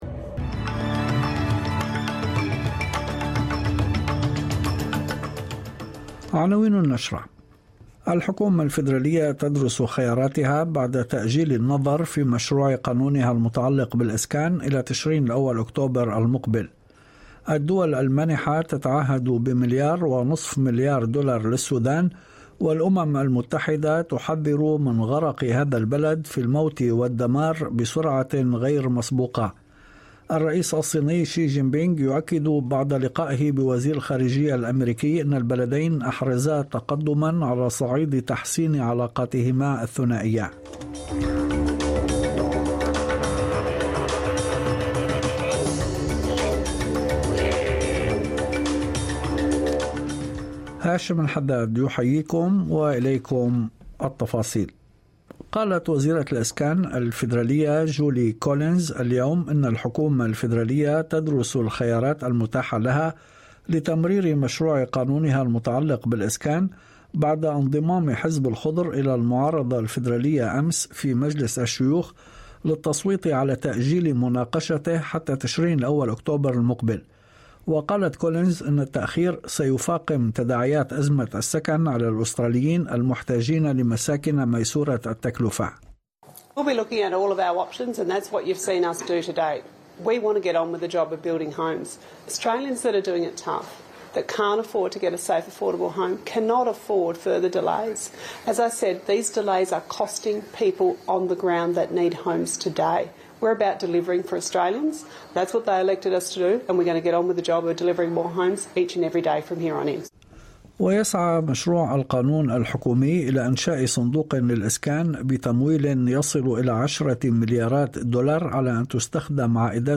نشرة أخبار المساء 20/06/2023